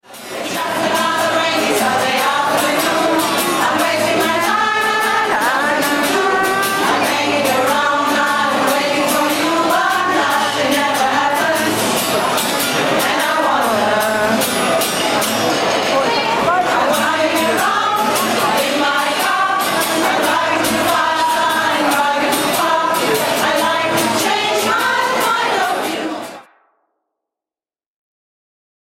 Malle Diven - Auftritt im DOC in Emden am 29.01.11